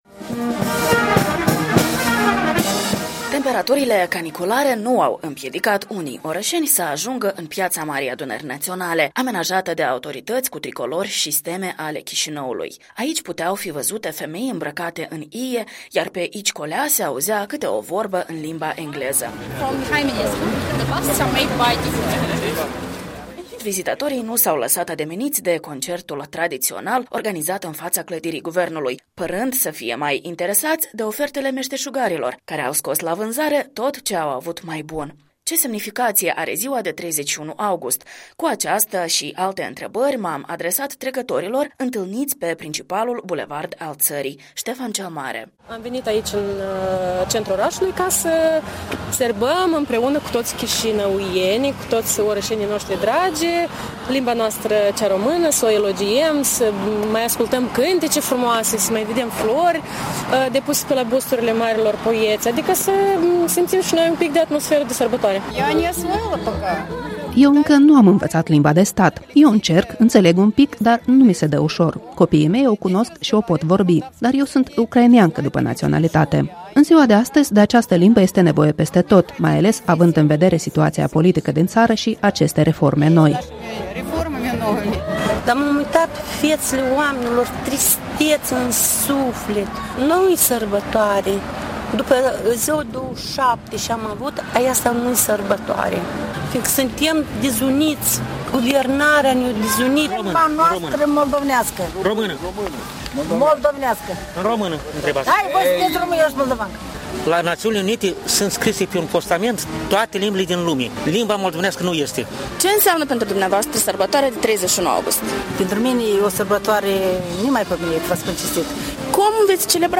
Reportaj de Ziua Limbii române la Chişinău.
Ce semnificație are ziua de 31 august? I-am întrebat pe trecătorii întâlniți pe principalul bulevard al țării, Ștefan cel Mare.